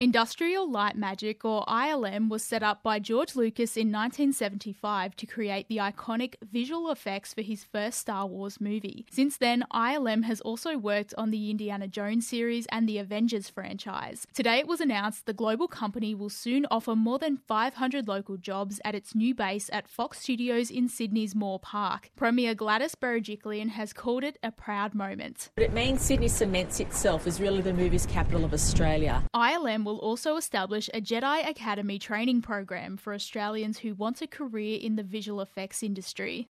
As Live